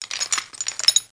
chainRattle26.mp3